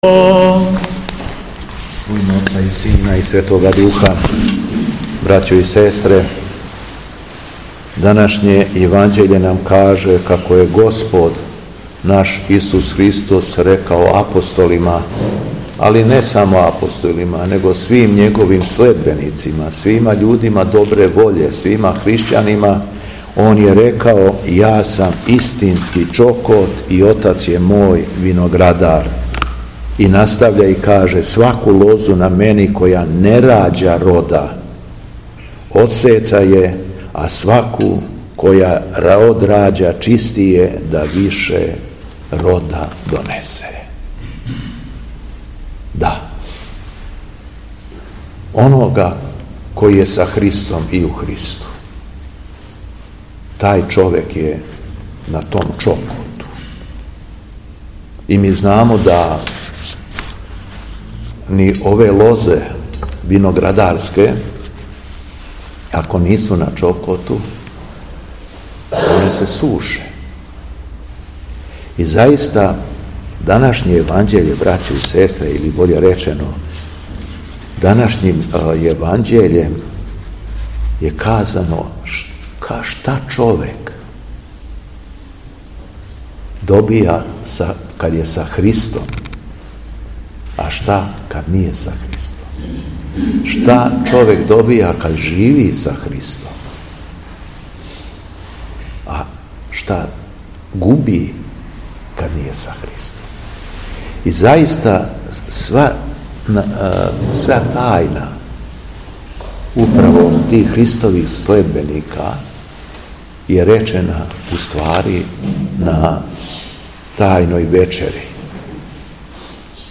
Беседа Његовог Високопреосвештенства Митрополита шумадијског г. Јована
Након прочитаног јеванђељског штива верном народу се надахнутим словом обратио Митрополит Јован: